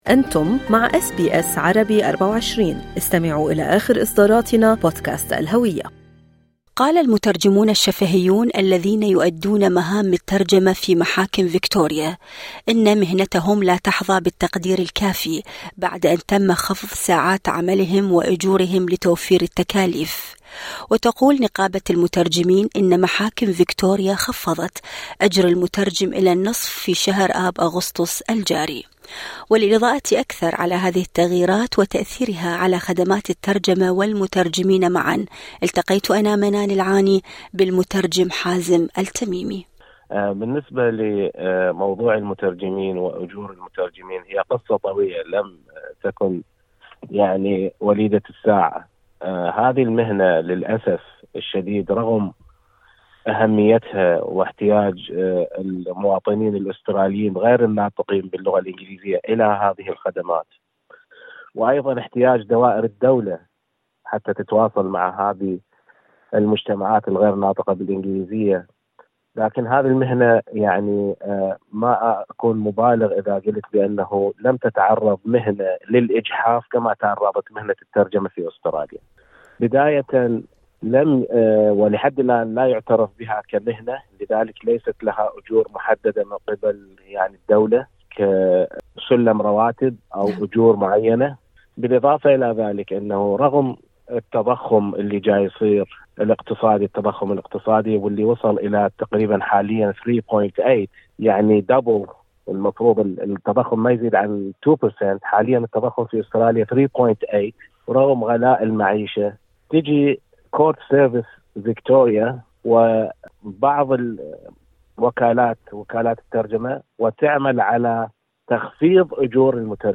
فكيف تؤثر هذه التغييرات على المترجم وخدمات الترجمة في المحاكم؟ المزيد في المقابلة الصوتية اعلاه